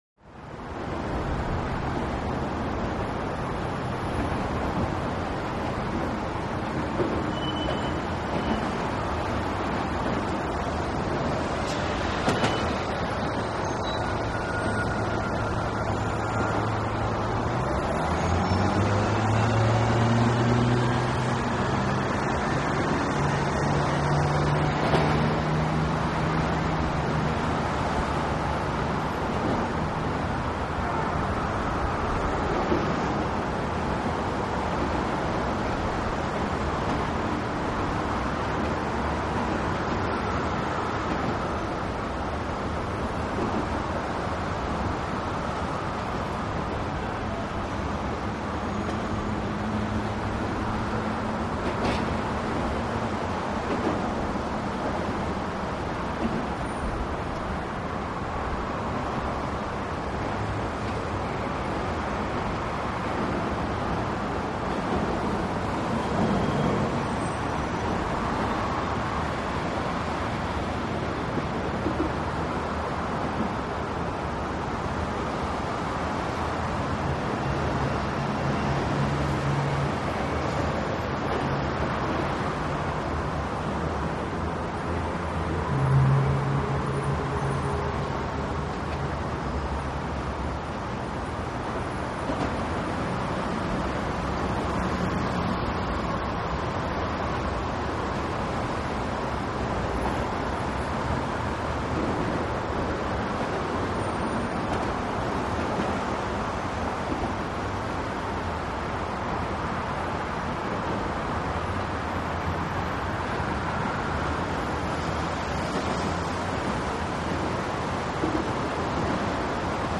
Under Bridge Point Of View, Heavy Traffic. Steady Airy Rush Of Noise, Less Defined Bys Than Track 1005-01. Medium Perspective Tire Clunks Over Strip In Road.